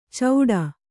♪ cauḍa